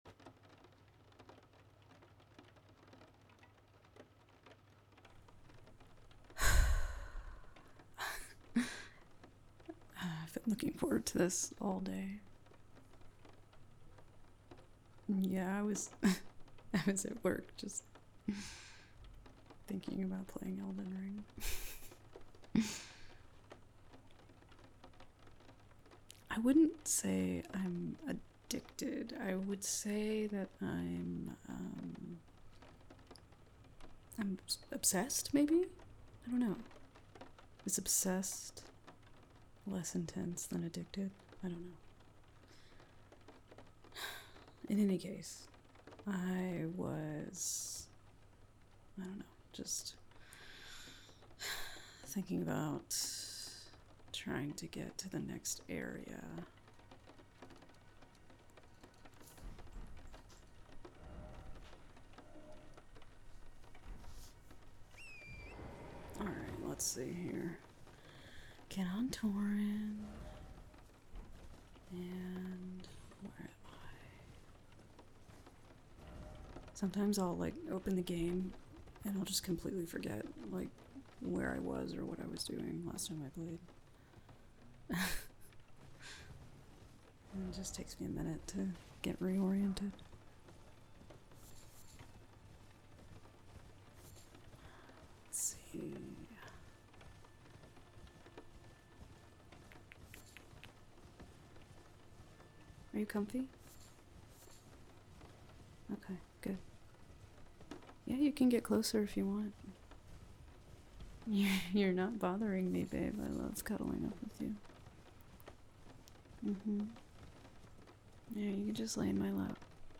Today is something soft and sleepy for those of us who need a little help getting to sleep each night.
I hope you enjoy the soothing sounds of Torrent, flasks being rapidly consumed, and the Black Knife Assassin lol.